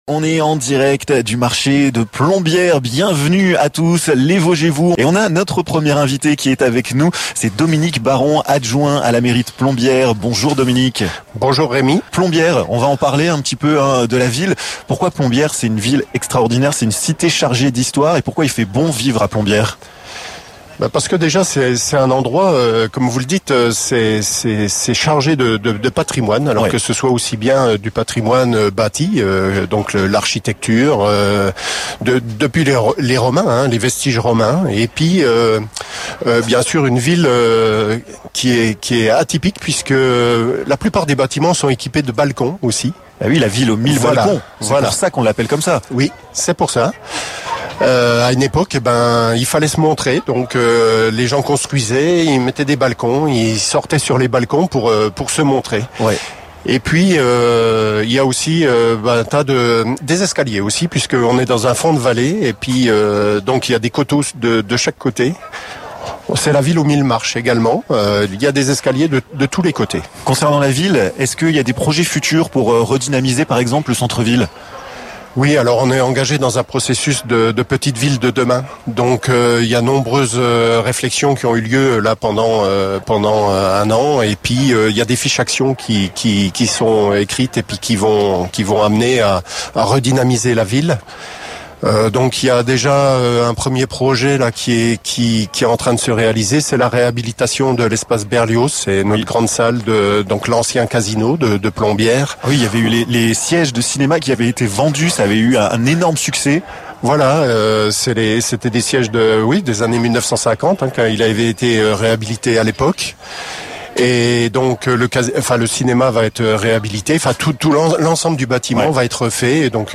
Revivez l'émission "les Vosges et vous" en direct du marché de Plombières!